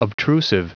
Prononciation du mot obtrusive en anglais (fichier audio)
Prononciation du mot : obtrusive